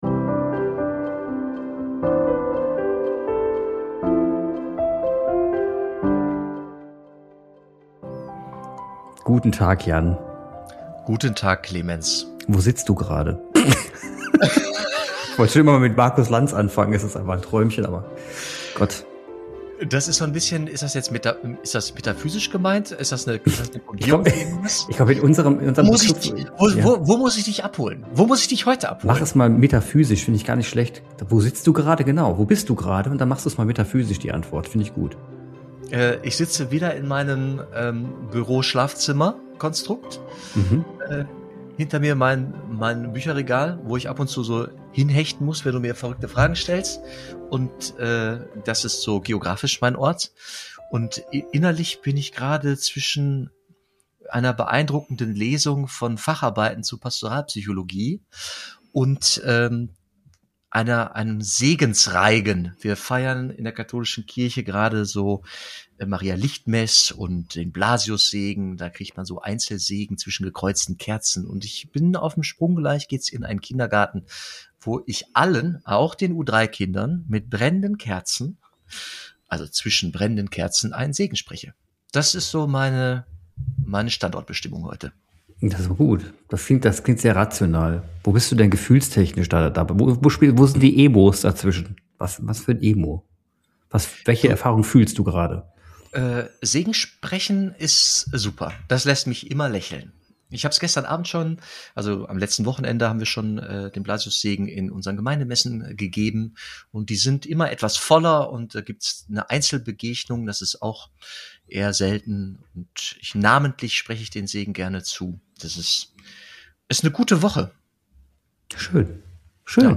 Ein Agnostiker erzählt offen von seiner überraschenden Annäherung an das gemeinsame Beten. Ein Priester ordnet diese Erfahrung theologisch ein – mit Blick auf kommunikative Theologie, Fürbitten, digitale Gottesdienste und die gesellschaftliche Kraft der Seligpreisungen.